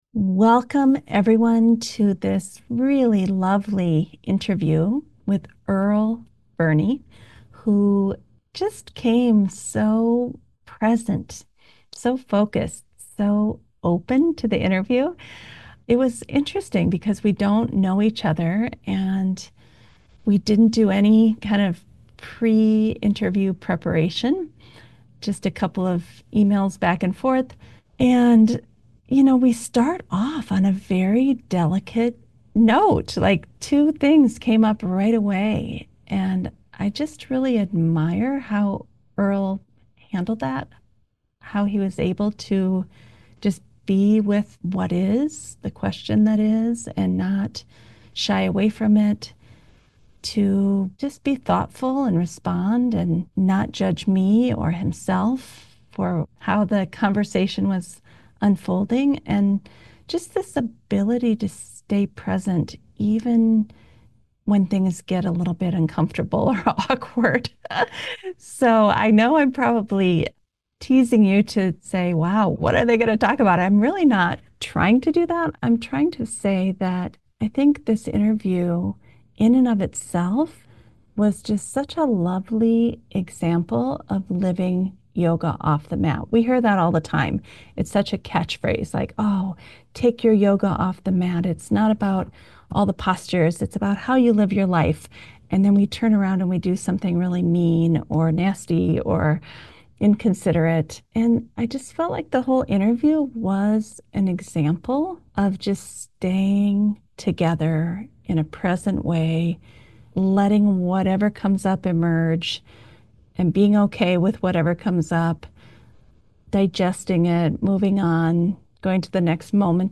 Walking Through the World with Grace: A Conversation